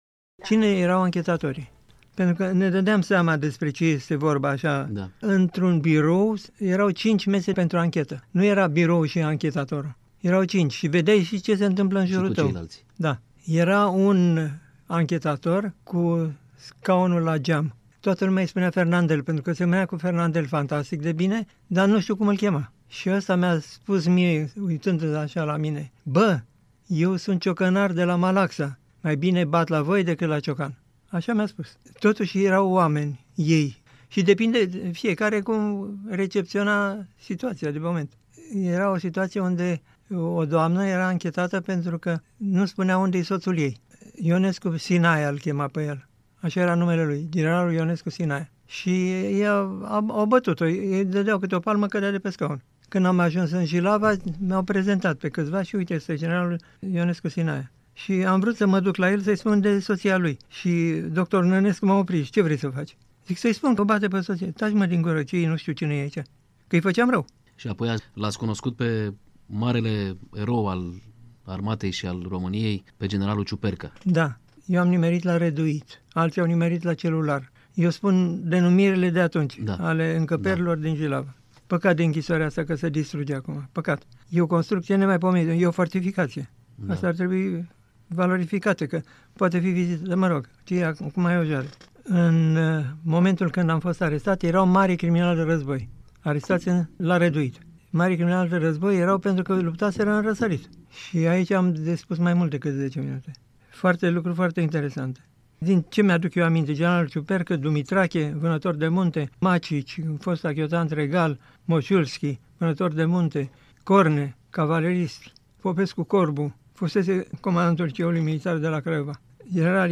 Interviu document cu fostul deținut politic